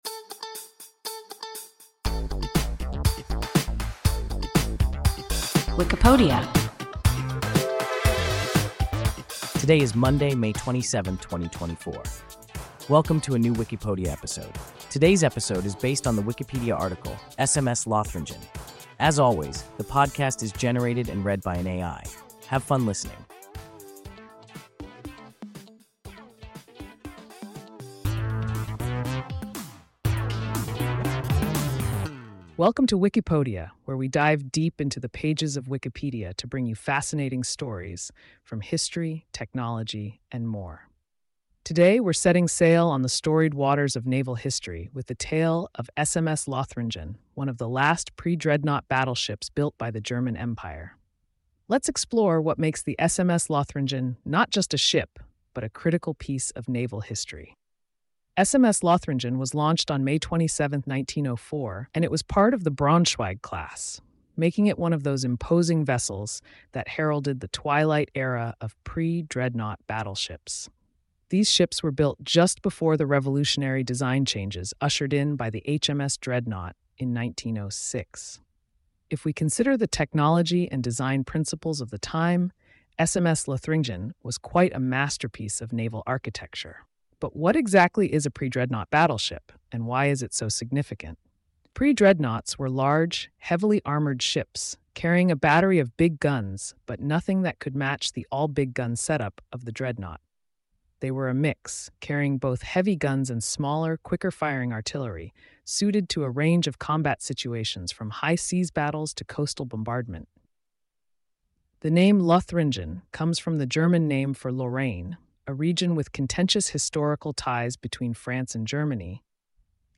SMS Lothringen – WIKIPODIA – ein KI Podcast